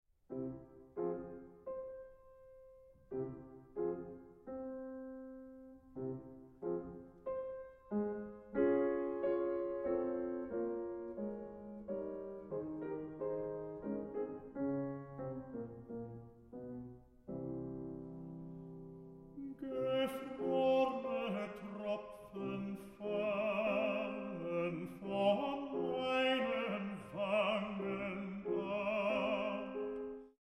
Tenor
Piano
This studio recording